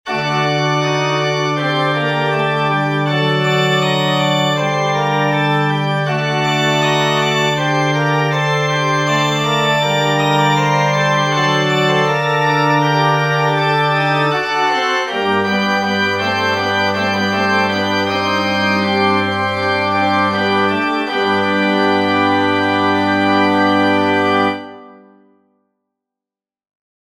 Der Bass ruht entweder auf der Dominante oder auch auf dem Grundton, während die anderen Stimmen kanonische Imitationen, aus dem Thema entnommen, hören lassen und sich allmählich dem ausgehaltenen Grundton anschließen.